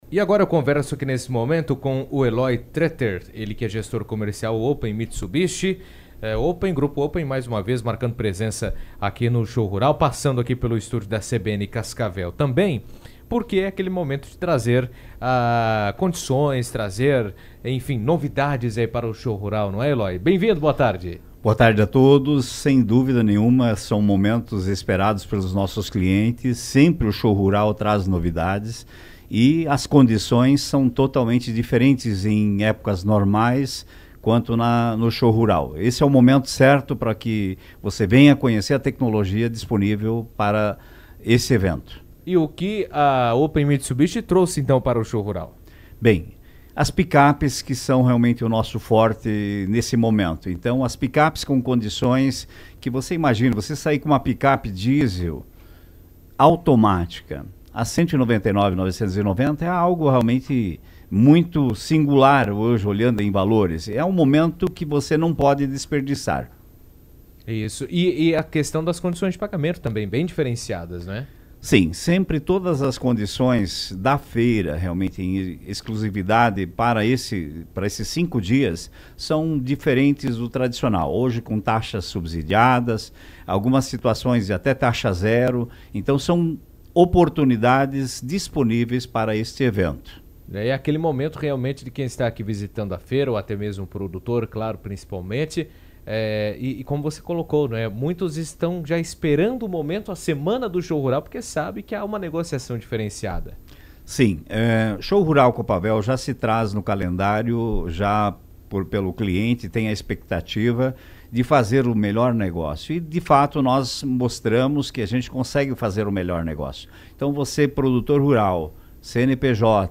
esteve no estúdio da CBN durante a 38ª edição do Show Rural Coopavel e detalhou as ofertas e condições especiais que a concessionária trouxe para os participantes da feira. Segundo ele, os pacotes incluem condições de financiamento e facilidades para produtores e empresários, reforçando o compromisso da marca com o agronegócio e o desenvolvimento regional.